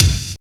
BIG BD 1.wav